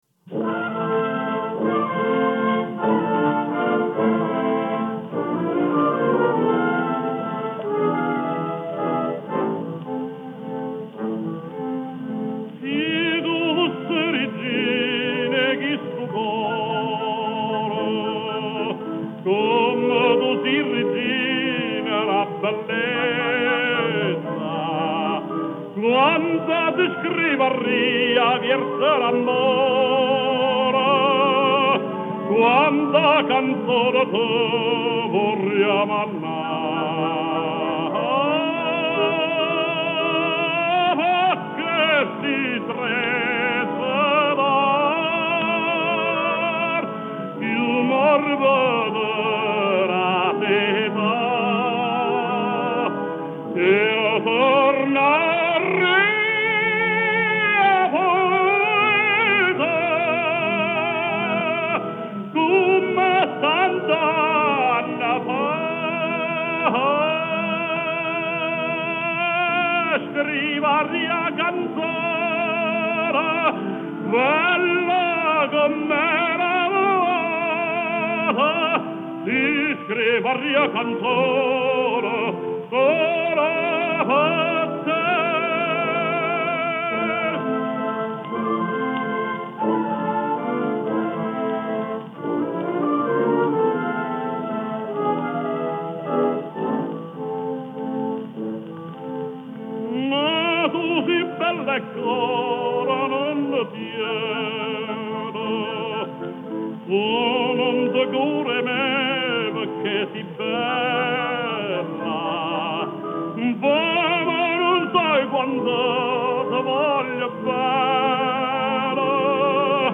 Жанр: Vocal